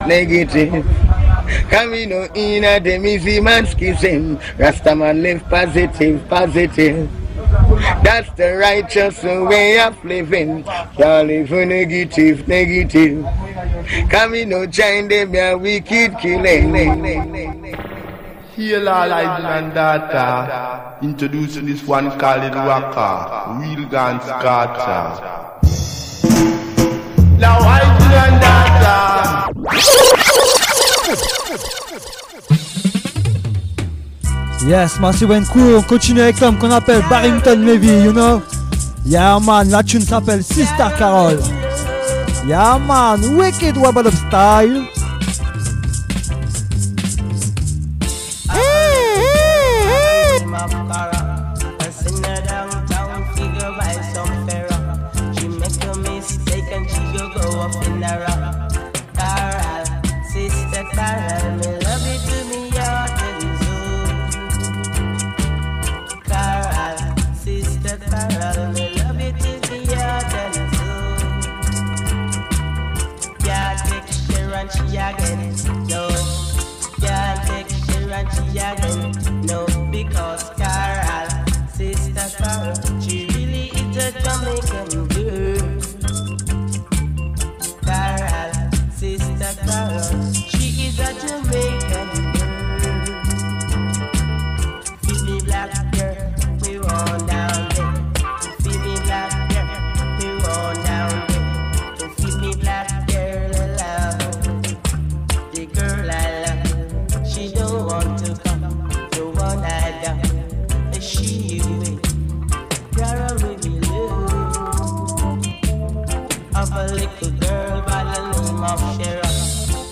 big vibe to african vibe & selecta vibe